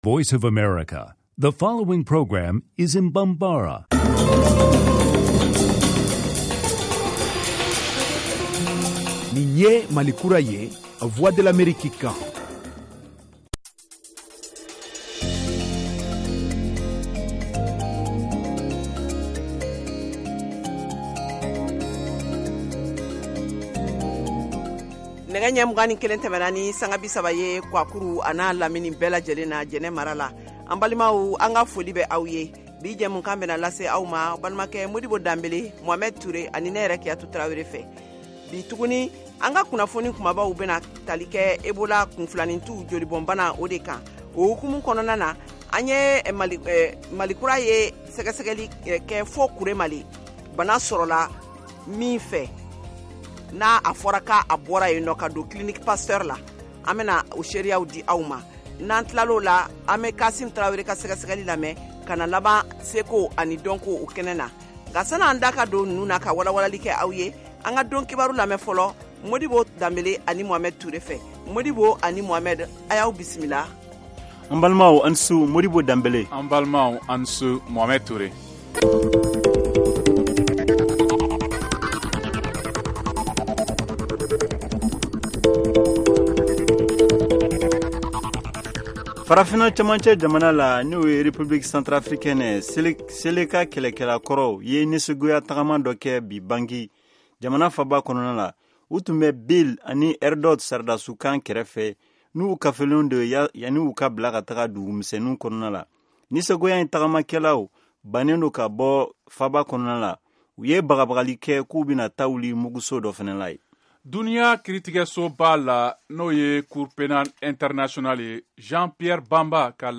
en direct de Washington